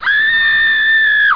1 channel
pain_7.mp3